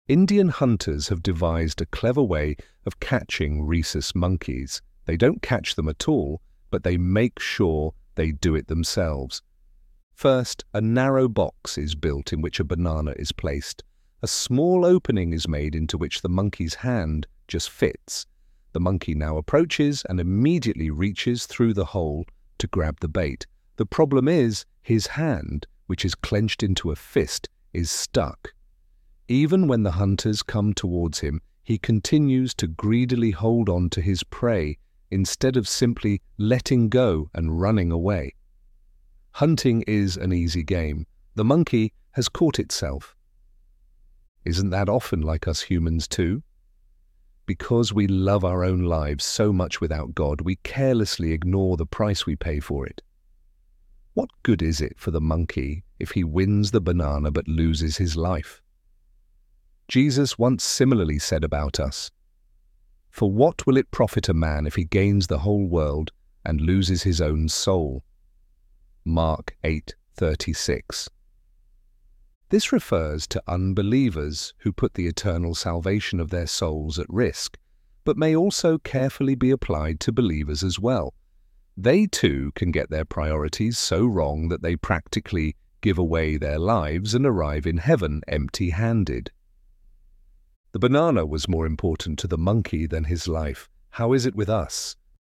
ElevenLabs_Catching_Oneself.mp3